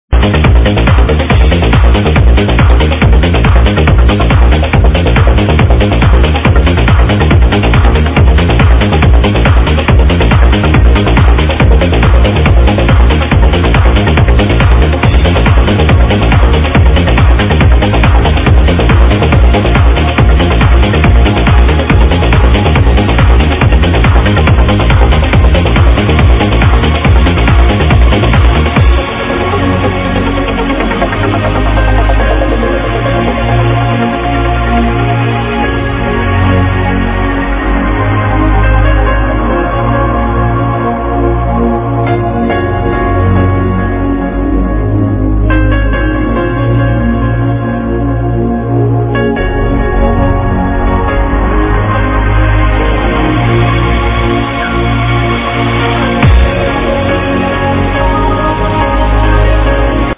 Piano Tune HELP :)
I heard it the other day during a liveset.